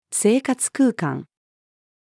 生活空間-female.mp3